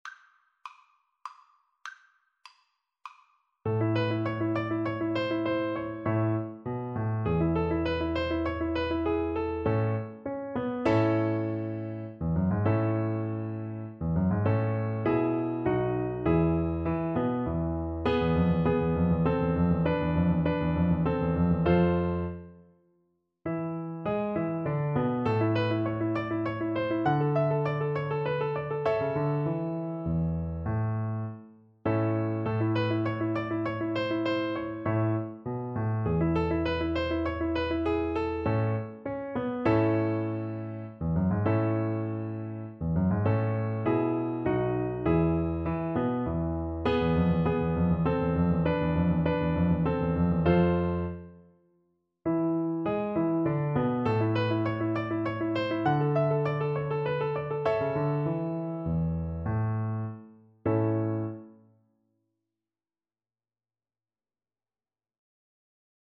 3/4 (View more 3/4 Music)
Classical (View more Classical Violin Music)